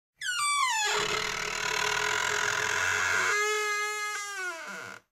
REDD SFX (3).mp3